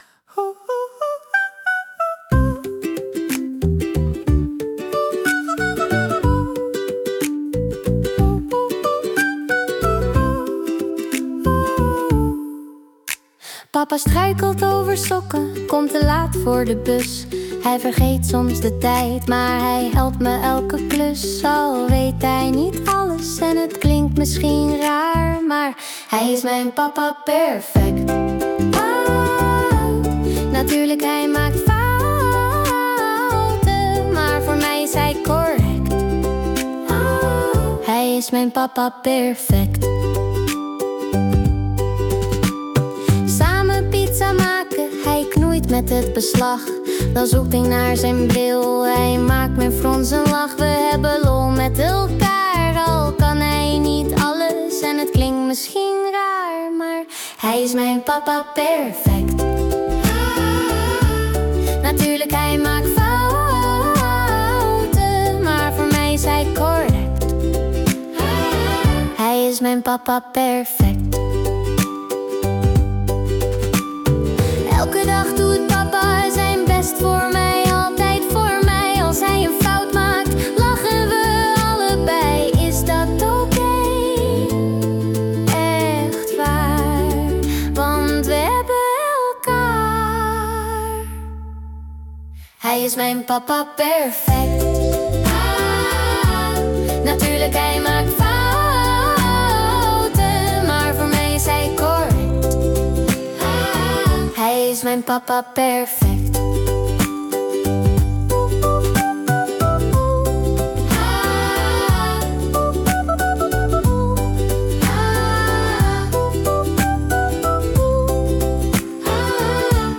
Song in atmosphere of the book
Created from original concepts/texts with AI.